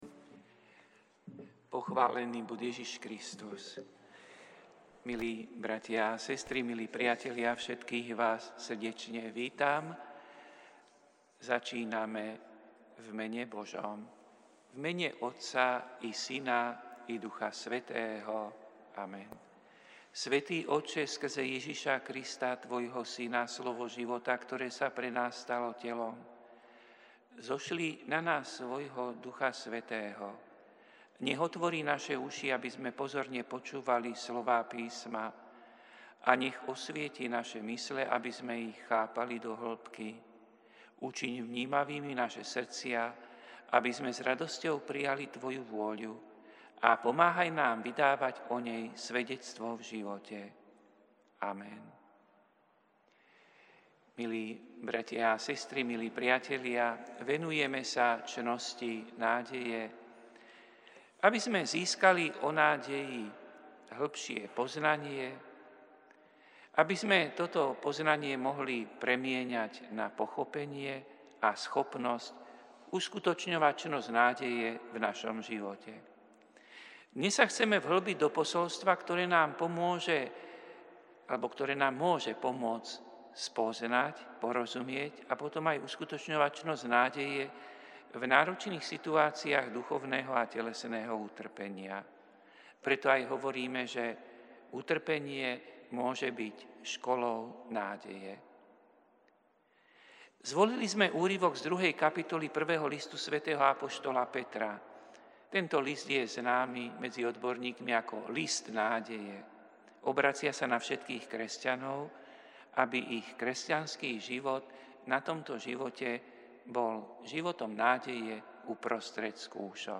Prinášame plný text a audio záznam z Lectio divina, ktoré odznelo v Katedrále sv. Martina 5. februára 2025.